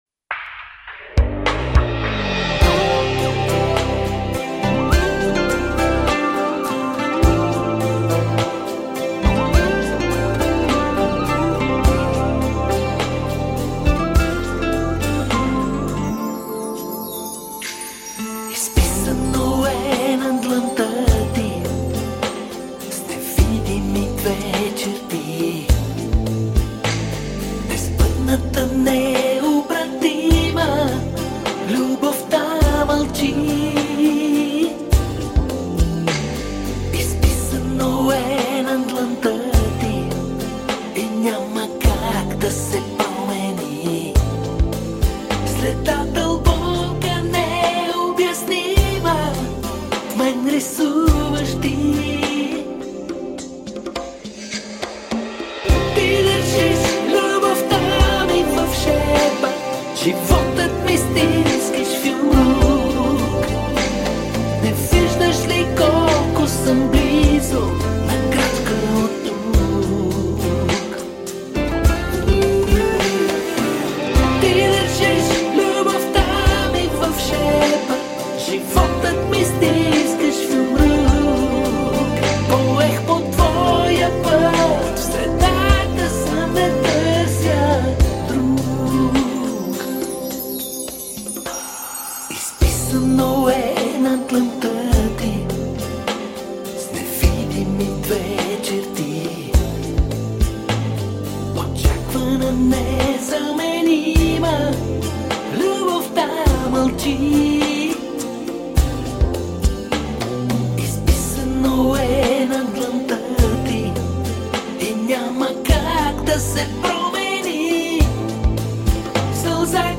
Есть и рок-композиции и джаз-рок. Есть диско-композиции.